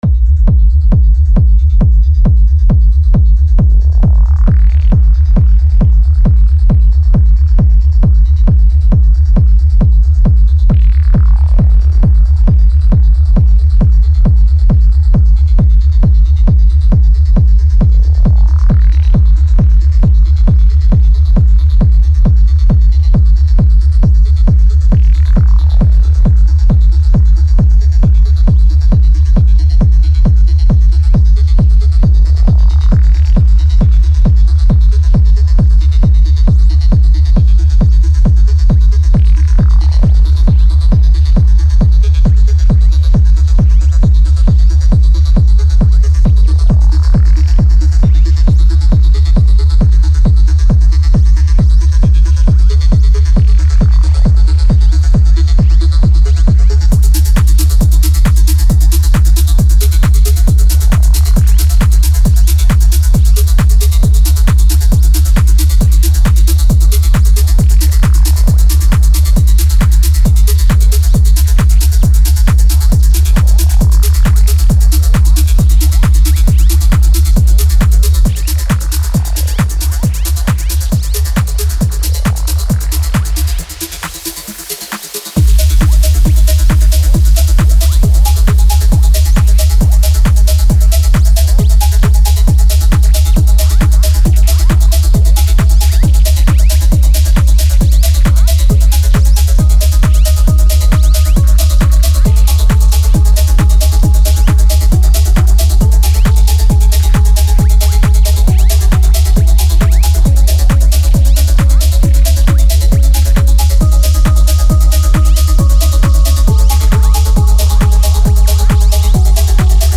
A steady, spacey journey through the stars.